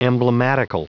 Prononciation du mot emblematical en anglais (fichier audio)
Prononciation du mot : emblematical